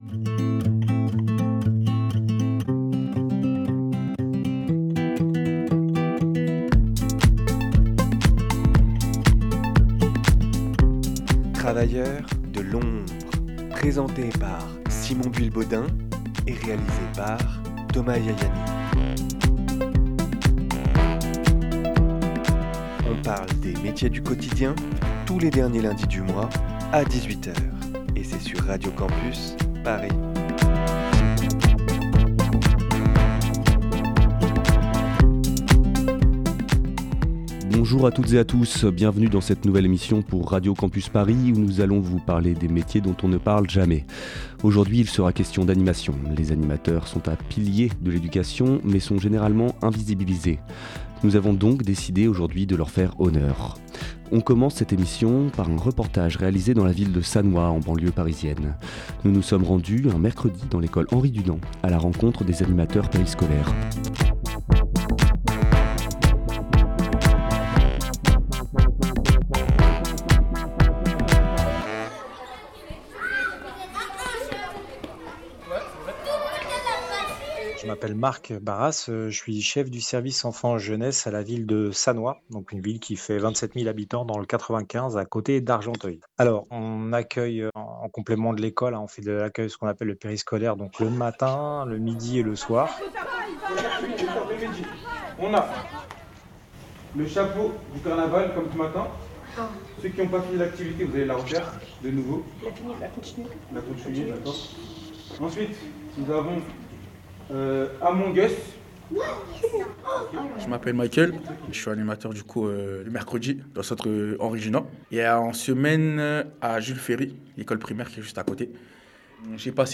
Premier épisode sur les métiers de l’animation ! Milieu réputé comme précaire, des animateurs et des cadres du milieu de la jeunesse nous expliquent leur situation.